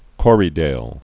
(kôrē-dāl)